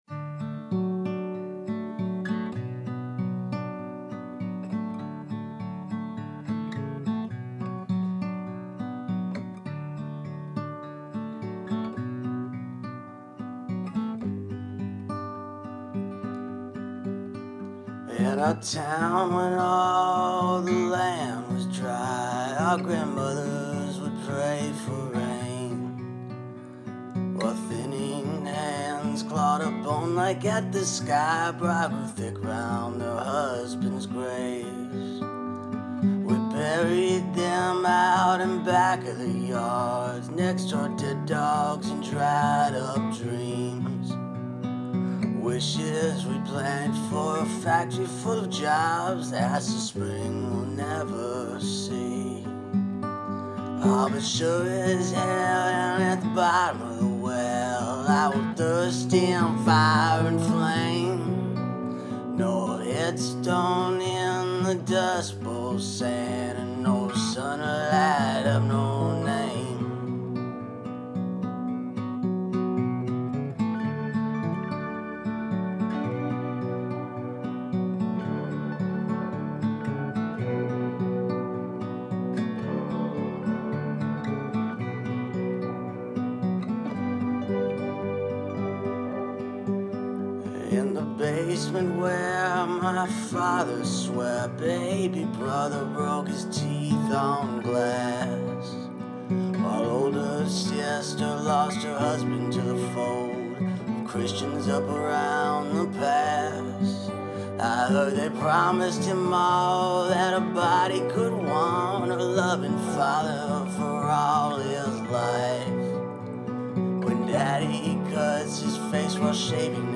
slide guitar and harmonica
spare finger picking